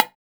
MH-AMB_SN1.wav